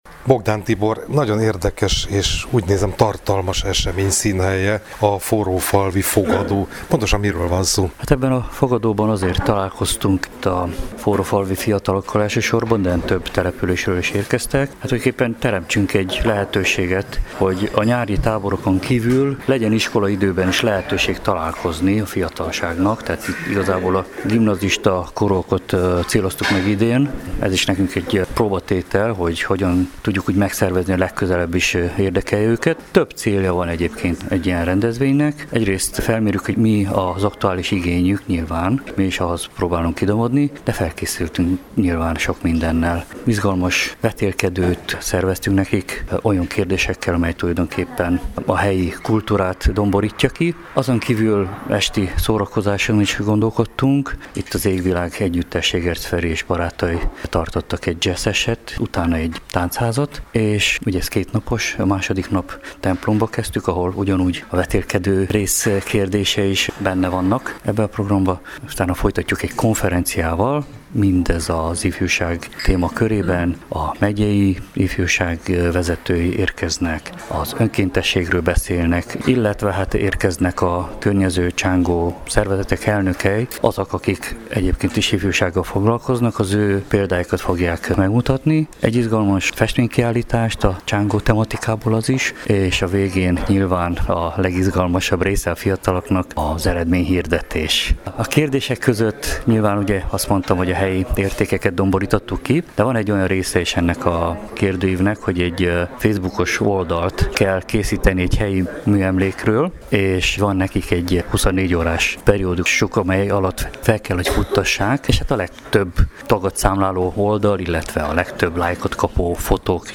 helyszíni összeállításában